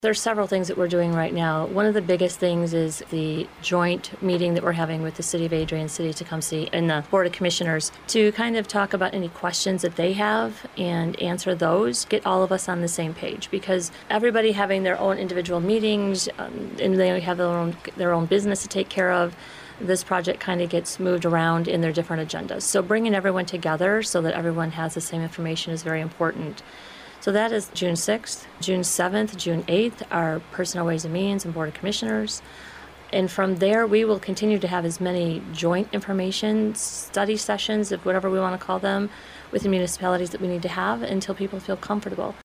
On a recent View Point program, Lenawee County Administrator Kim Murphy talked about the next steps that the County, and its Commissioners, will take with respect to the project…